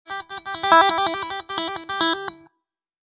Descarga de Sonidos mp3 Gratis: guitarra a 6.
descargar sonido mp3 guitarra a 6